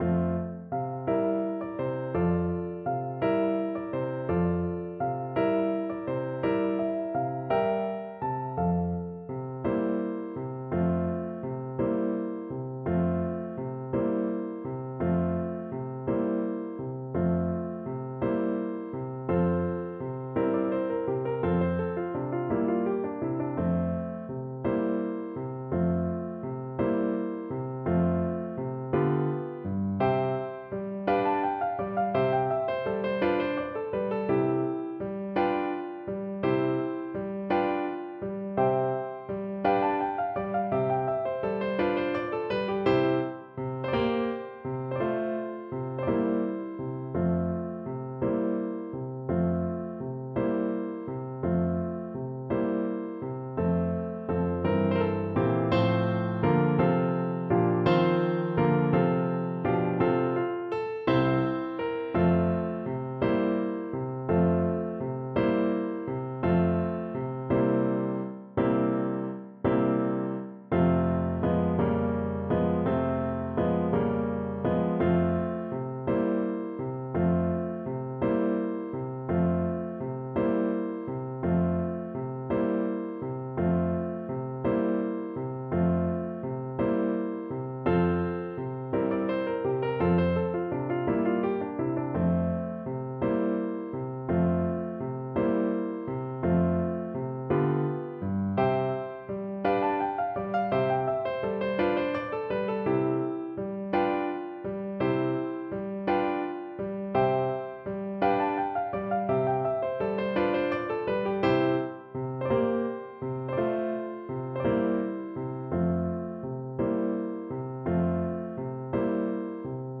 French Horn version
Moderato quasi allegretto .=c.56
6/8 (View more 6/8 Music)
Classical (View more Classical French Horn Music)